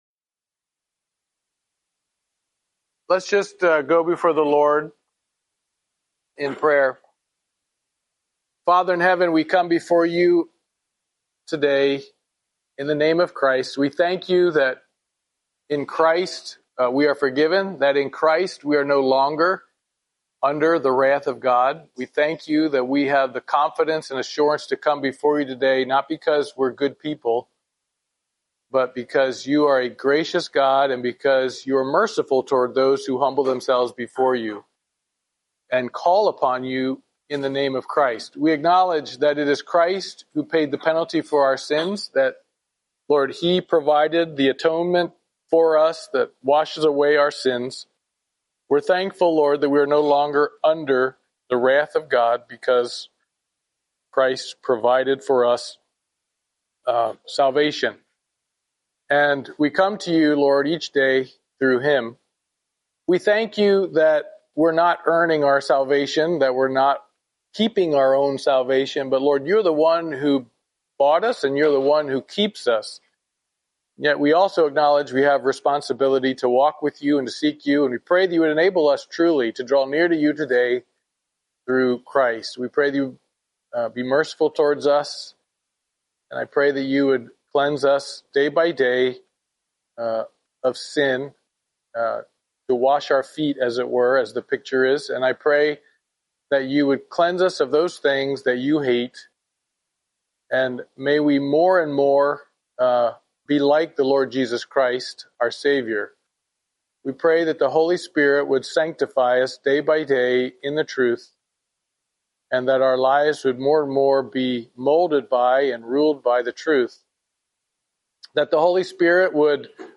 Ecclesiastes Service Type: Wednesday Morning Bible Study Topics